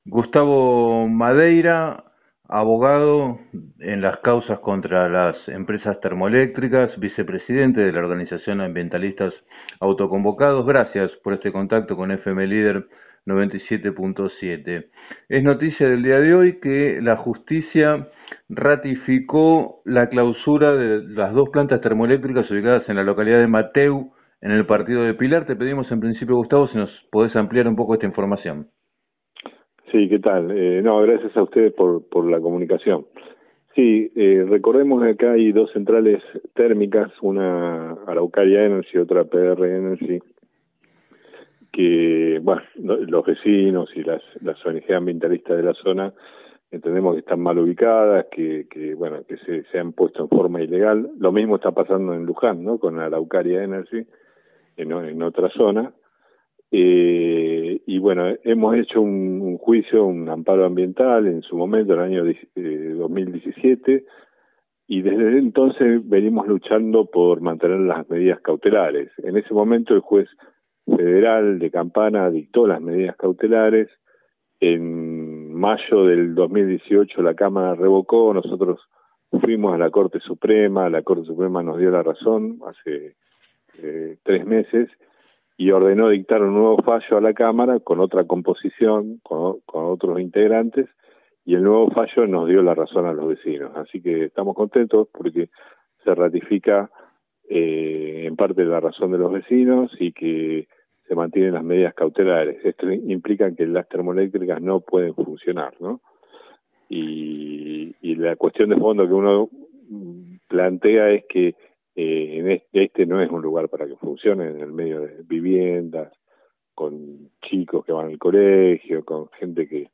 En declaraciones a FM Líder 97.7, el abogado